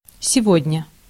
Ääntäminen
Vaihtoehtoiset kirjoitusmuodot nowaday now a days now-a-days (vanhentunut) nowadayes (rikkinäinen englanti) nowdays Synonyymit now currently these days in this day and age Ääntäminen US : IPA : [ˈnaʊ.ə.ˌdeɪz] UK : IPA : /ˈnaʊ.ə.deɪz/ US : IPA : /ˈnaʊ.ə.deɪz/